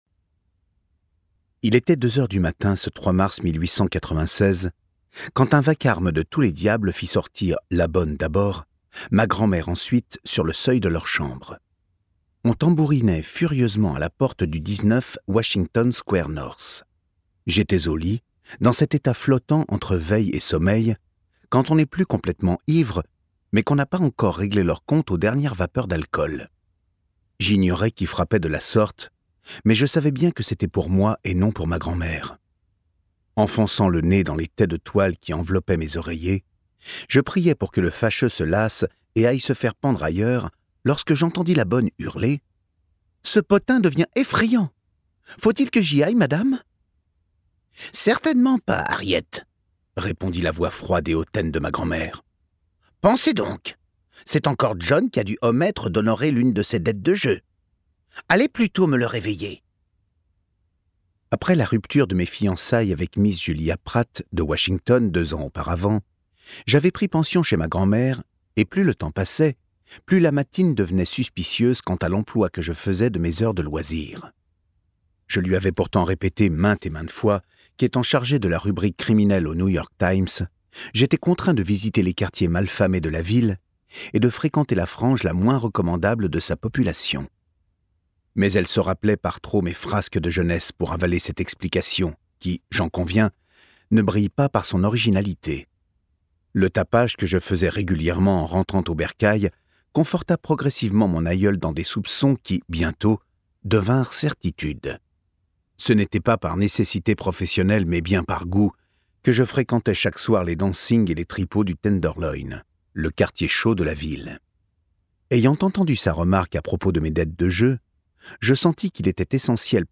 Extrait gratuit - L'Aliéniste de Caleb CARR